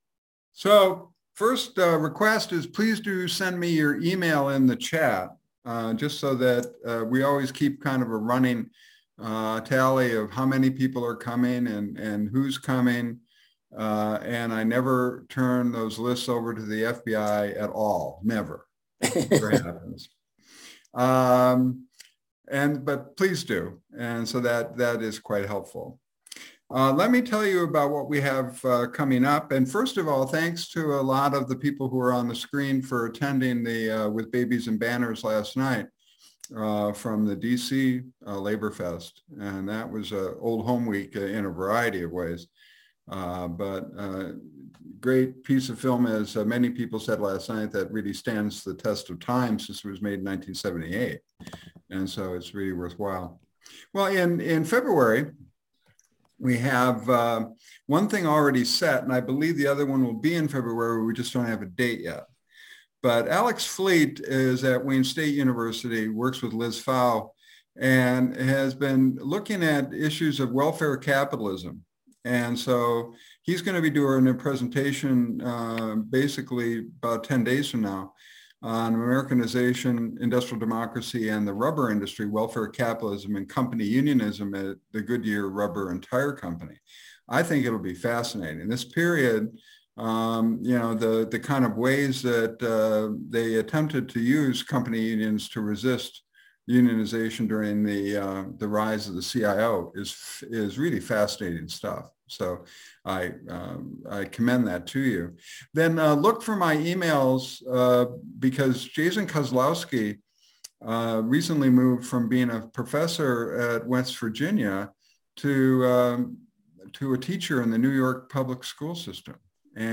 Sound recordings Lectures